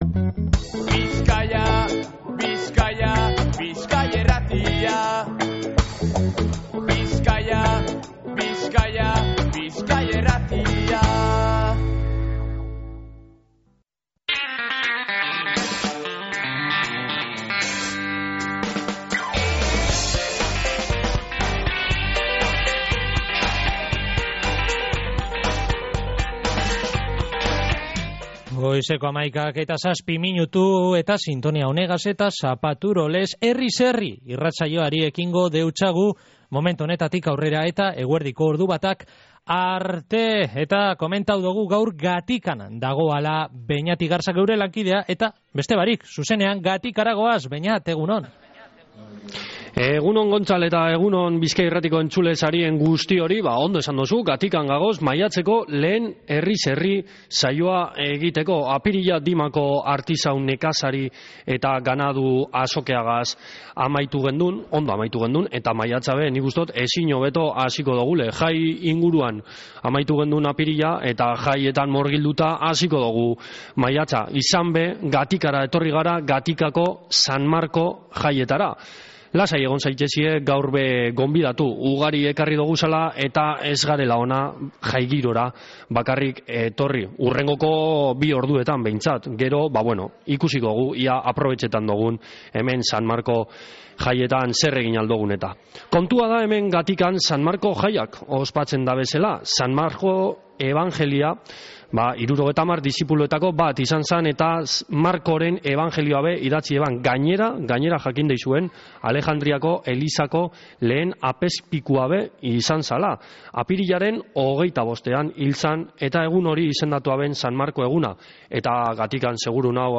Gatikako San Marko jaietan egon gara maiatzeko lehen Herriz Herri saioan